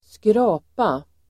Uttal: [²skr'a:pa]